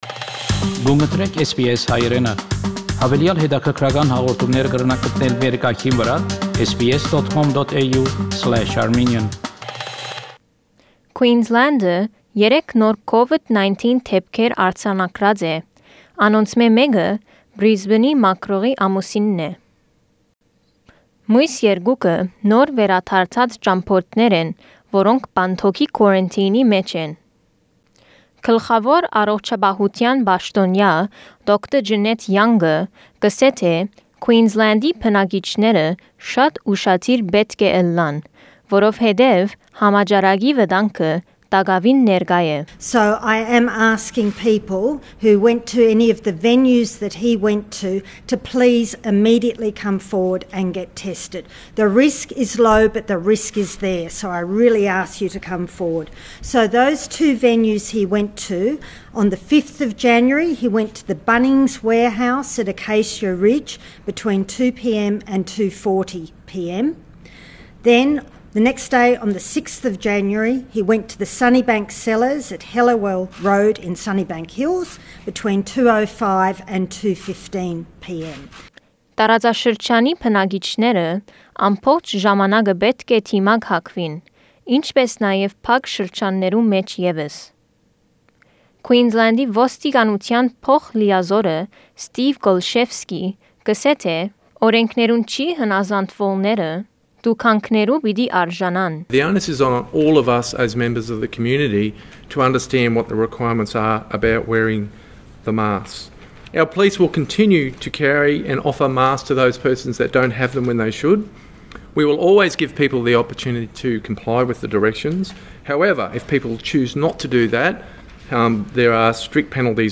SBS Armenian news bulletin – 12 January 2021
SBS Armenian news bulletin from 12 January 2021 program.